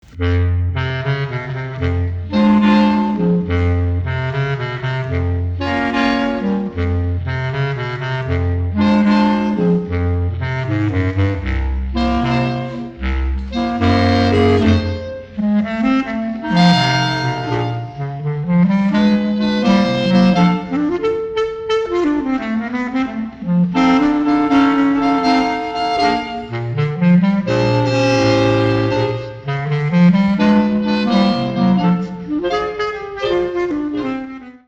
Klarinettenquartett/Saxophonquartett